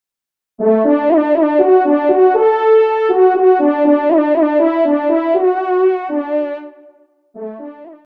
FANFARE
Genre : Fanfare d’Équipages
Extrait de l’audio « Ton de Vènerie »